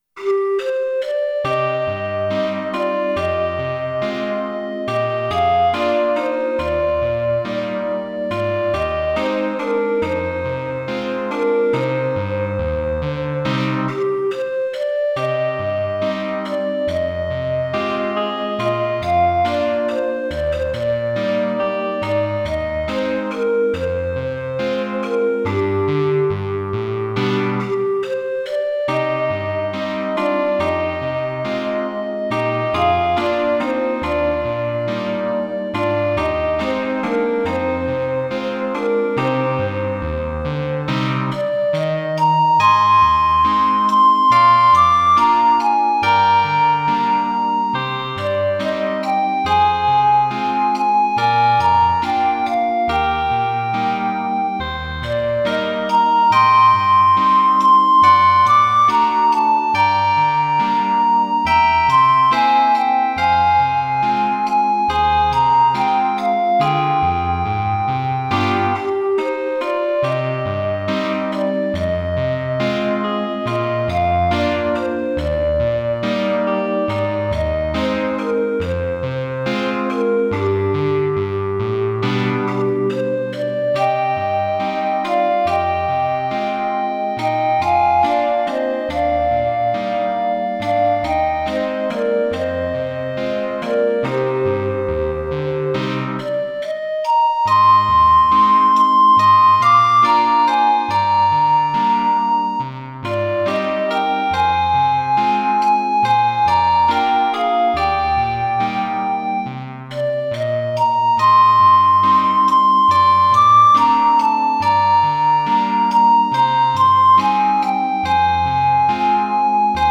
ЗВУКОВЫЕ ИЛЛЮСТРАЦИИ НОТ
НЕСКОЛЬКО МЕЛОДИЙ ВЕЧЕРНЕГО НАСТРОЕНИЯ.